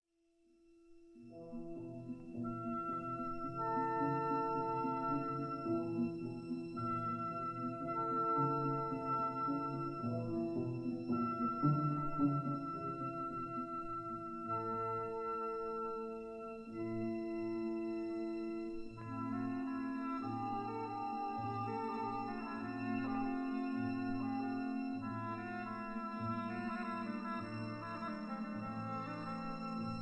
1962 stereo recording